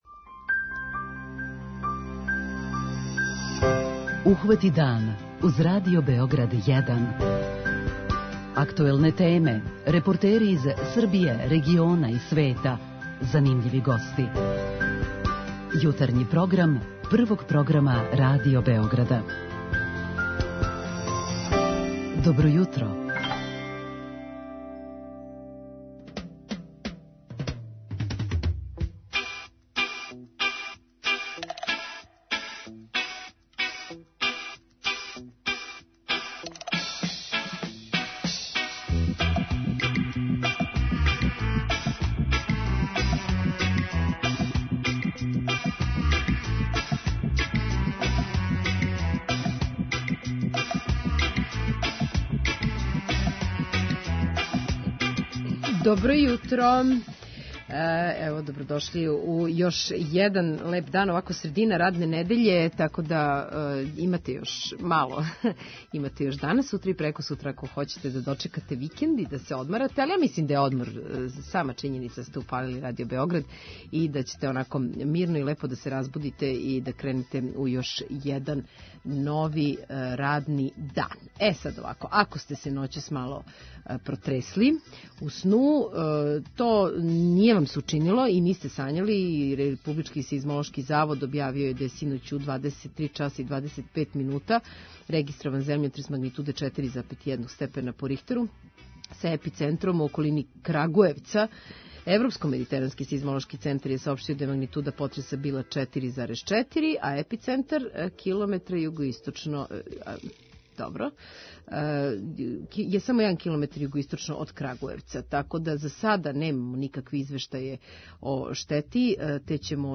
У Питању јутра са вама разговарамо о томе какав ваздух удишете. преузми : 37.78 MB Ухвати дан Autor: Група аутора Јутарњи програм Радио Београда 1!